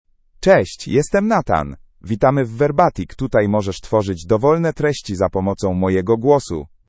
MalePolish (Poland)
Nathan is a male AI voice for Polish (Poland).
Voice sample
Listen to Nathan's male Polish voice.
Male
Nathan delivers clear pronunciation with authentic Poland Polish intonation, making your content sound professionally produced.